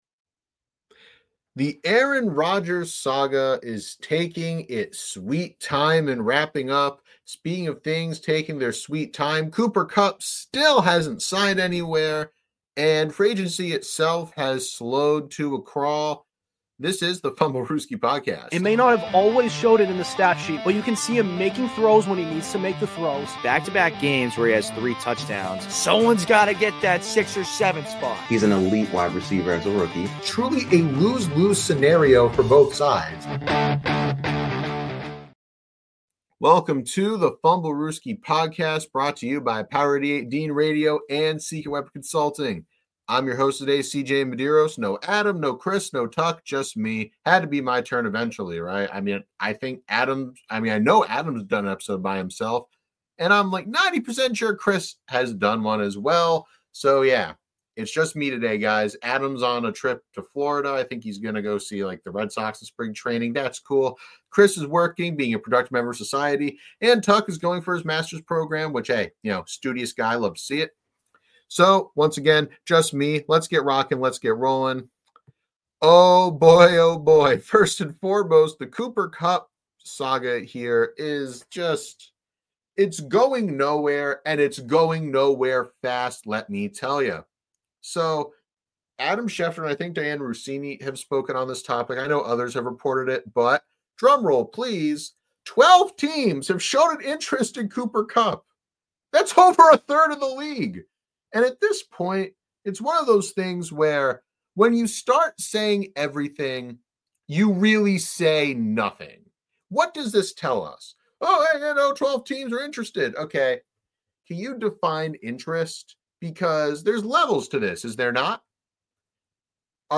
An NFL podcast where we discuss all things pro-football-related, including recent news and hot takes. Hosted by three college kids